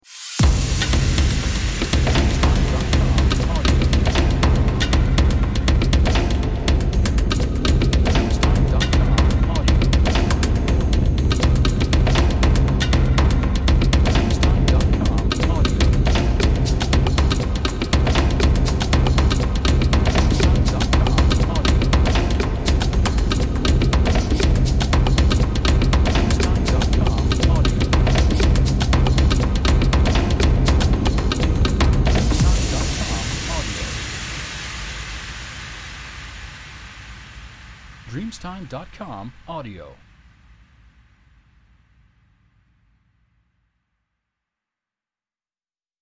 Only Percussion